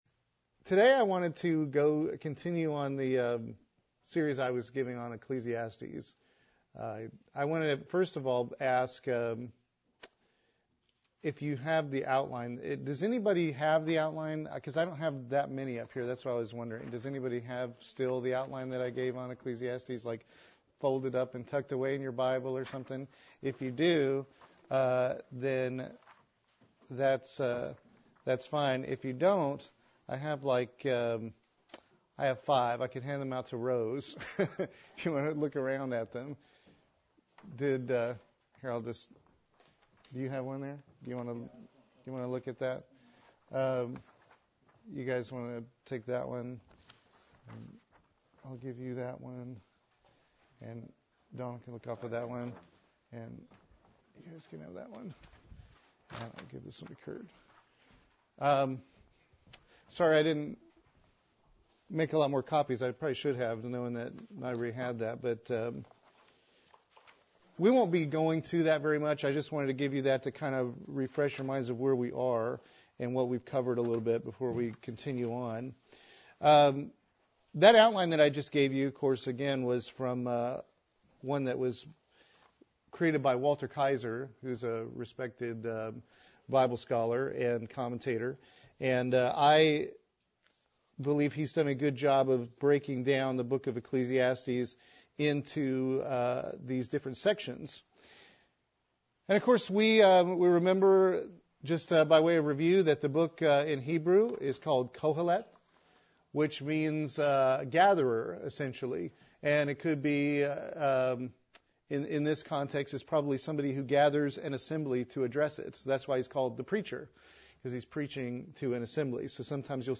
Sermons
Given in Columbia - Fulton, MO